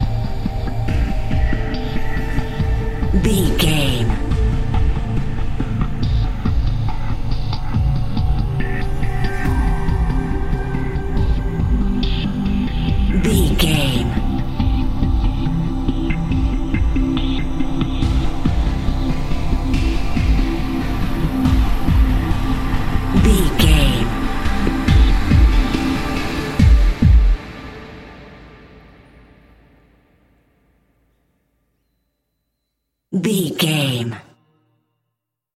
Survival horror
Thriller
Ionian/Major
synthesiser
drum machine